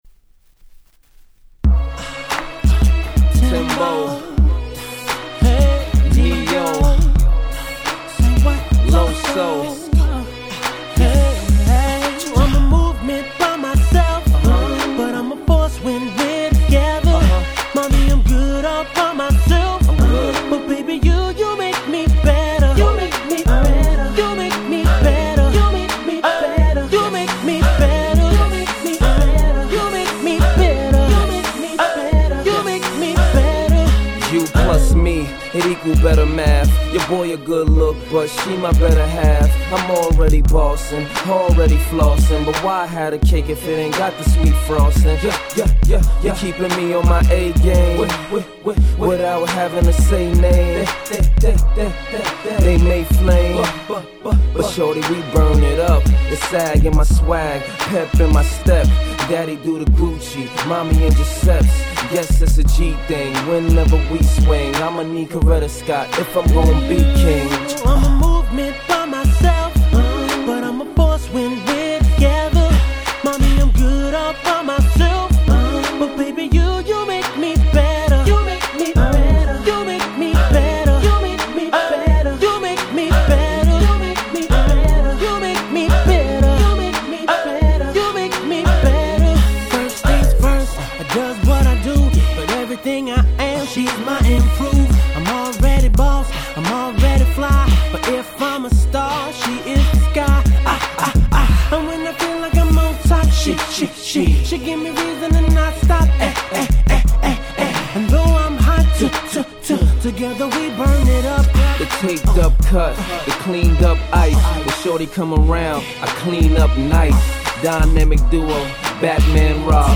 07' Smash Hit Hip Hop !!